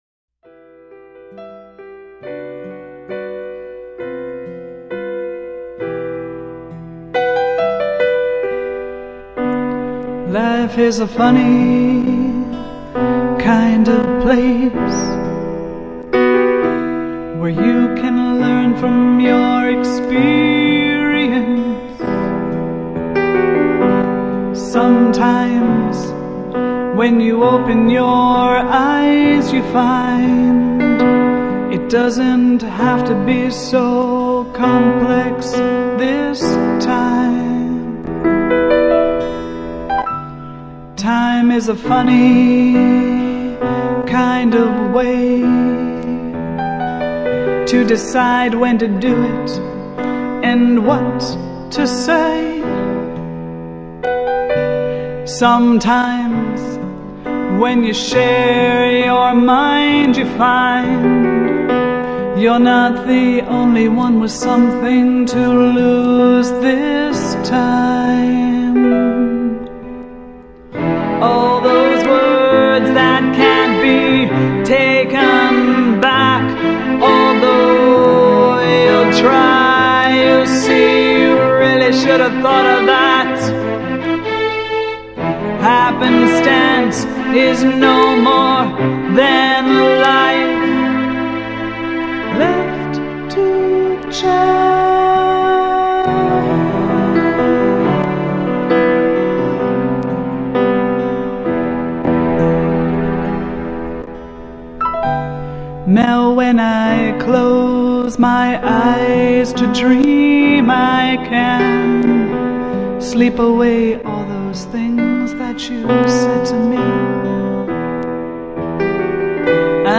I'm playing piano